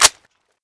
Index of /destroyers/sound/weapons/m4a1
boltrelease.wav